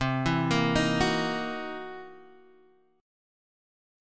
C7#9b5 chord